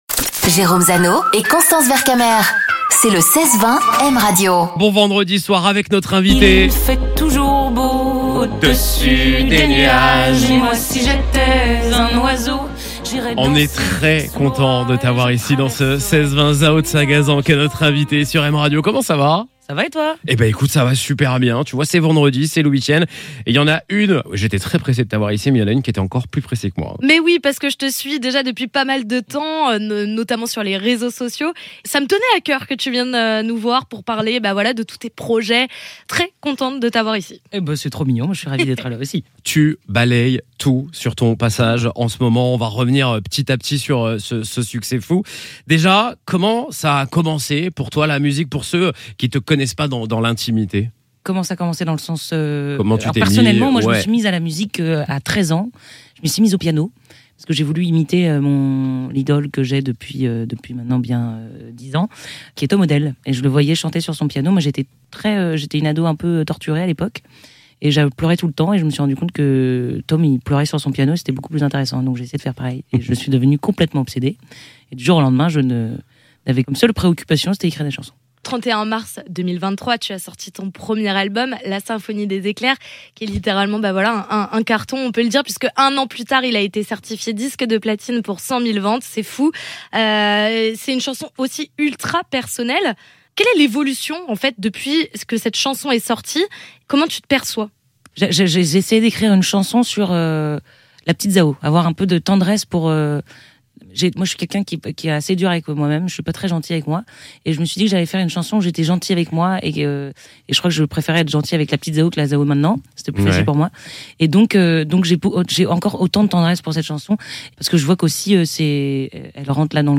Elle est l'invitée du 16/20 sur M Radio pour évoquer ses projets et cette folle année qui vient de s'écouler